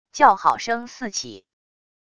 叫好声四起wav音频